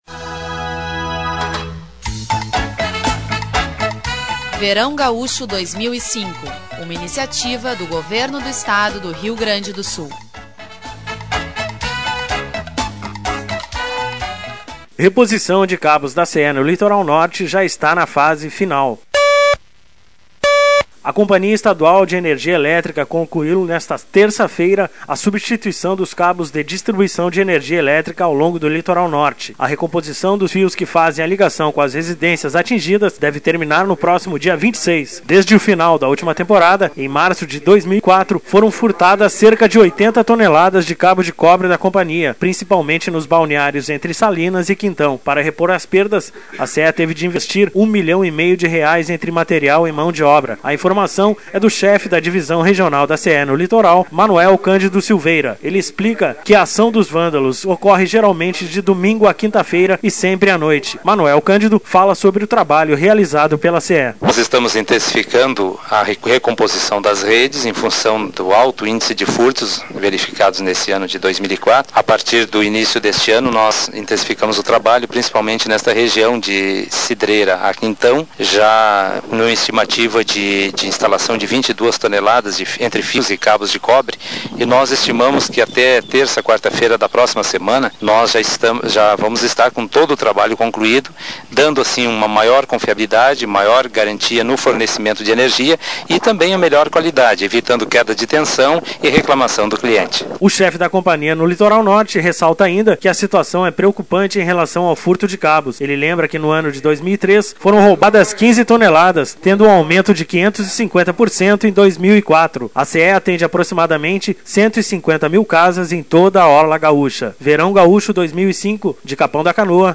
A Companhia Estadual de Energia Elétrica concluiu nesta terça-feira a substituição dos cabos de distribuição de energia elétrica ao longo do Litoral Norte. Sonora